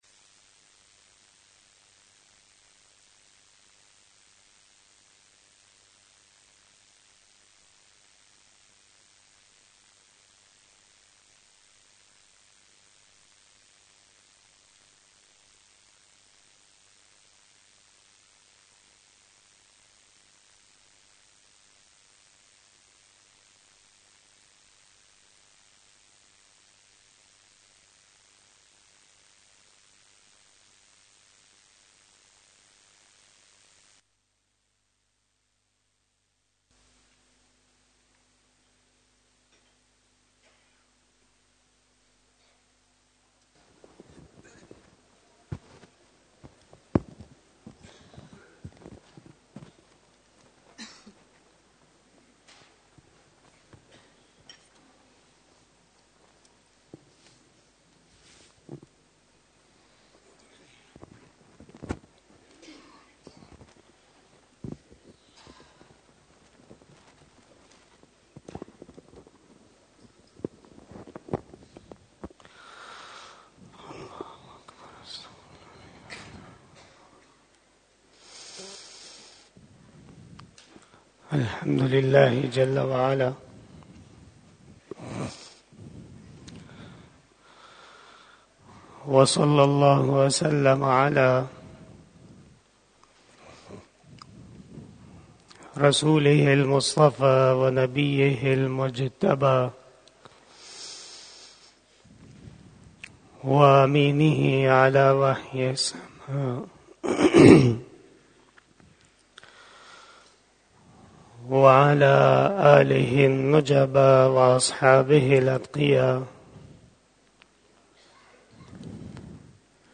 05 BAYAN E JUMMA 03 February 2023 (11 Rajab 1444H)
12:14 PM 311 Khitab-e-Jummah 2023 --